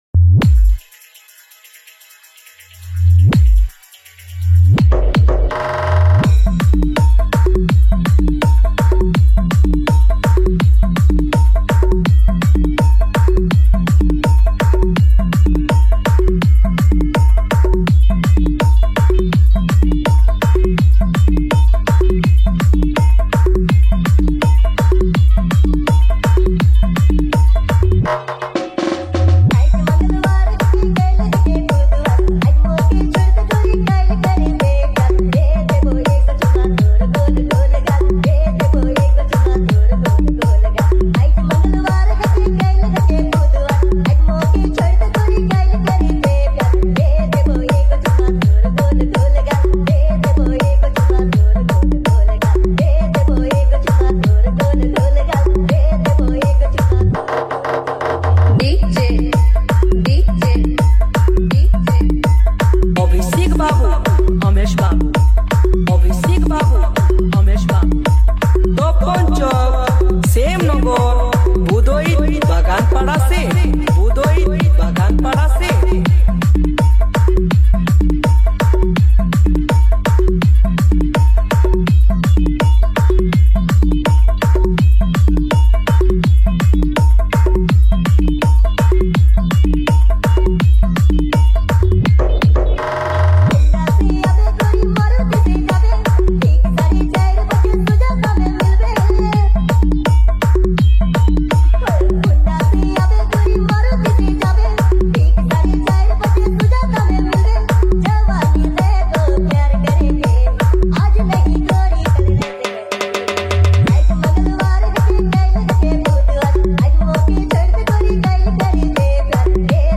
Dj Remixer
New Santali Dj Songs